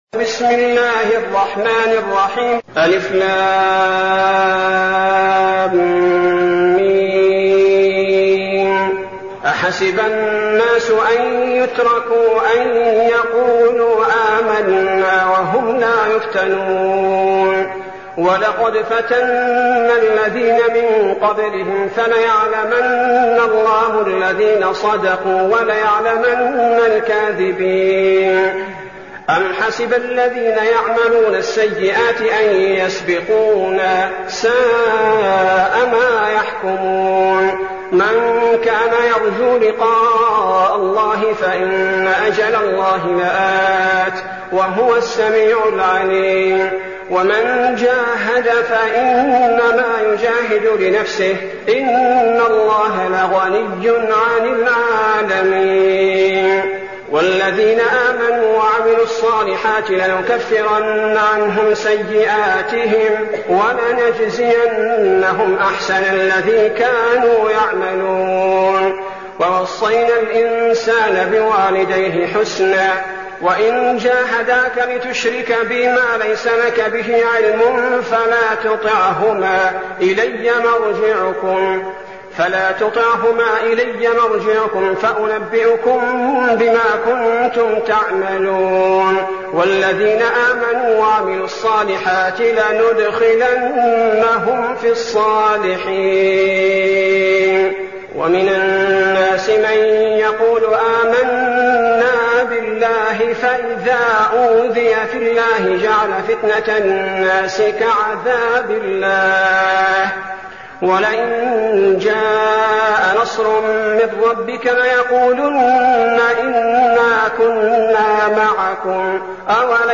المكان: المسجد النبوي الشيخ: فضيلة الشيخ عبدالباري الثبيتي فضيلة الشيخ عبدالباري الثبيتي العنكبوت